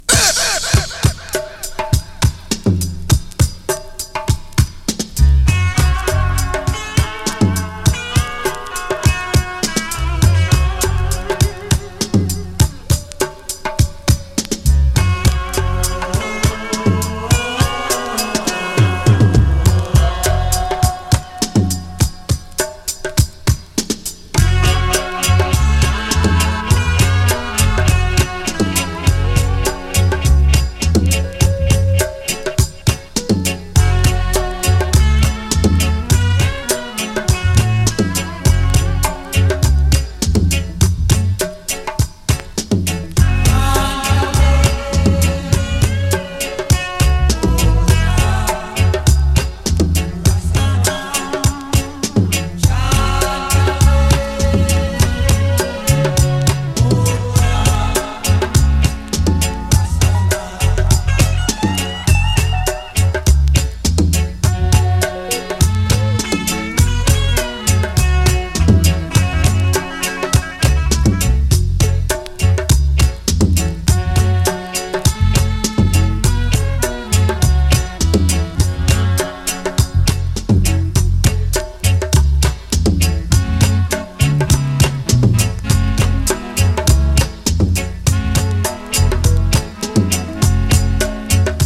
play dub